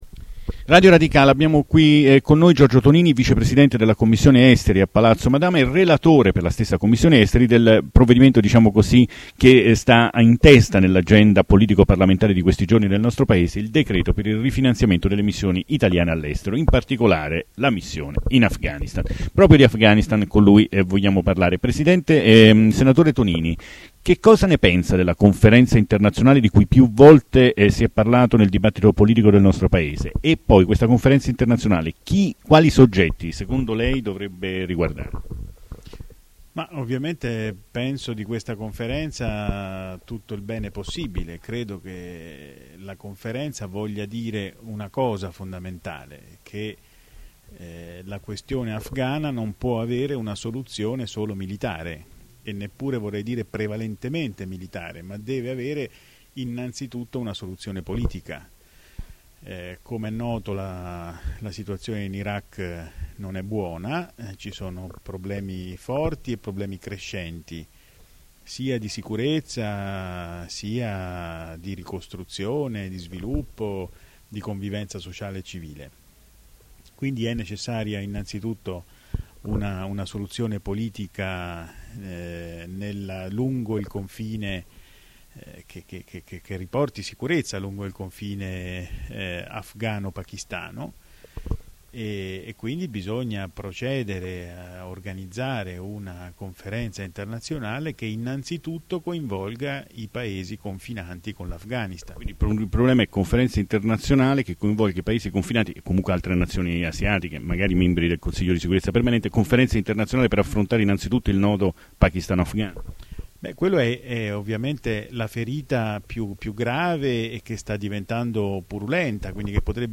L'intervista a Radio Radicale alla vigilia del voto in Senato (Mp3 4,31 Mb)